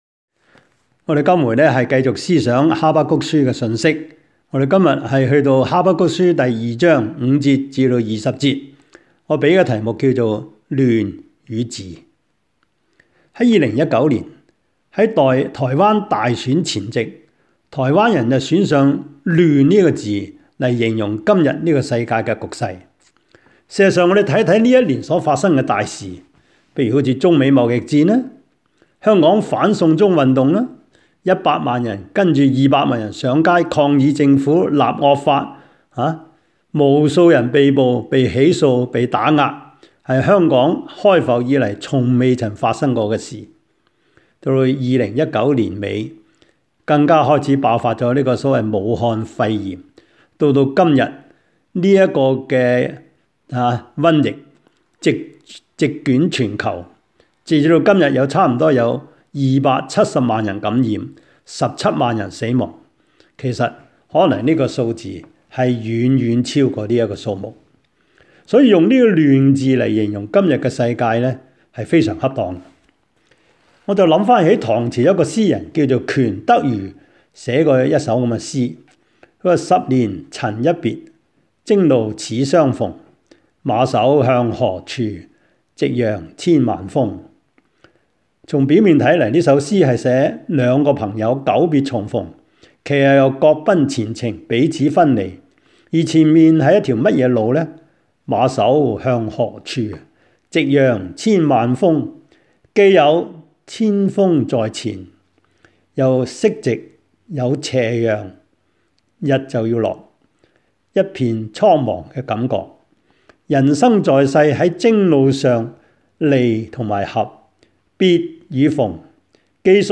Habakkuk-Sermon-5.mp3